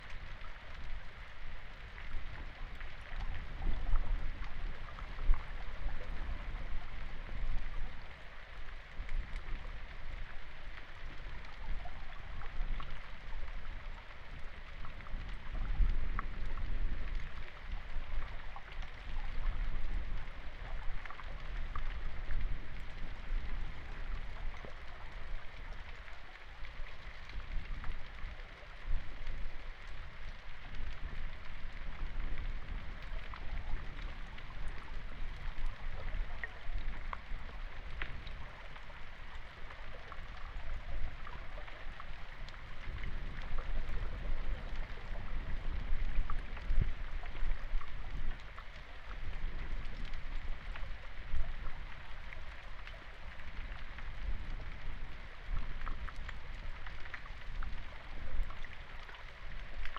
Audio from hydrophone during no bedload movement (Low dB)
This audio was recorded by the hydrophone installed in the North Santiam River at Greens Bridge, near Jefferson, Oregon during low flow in late January 2023. The sounds are of river water flowing over gravel and cobbles along the river bottom.
Audio collected when sediment sampling confirmed no bedload movement.
Hydrophone_lowDecibles.mp3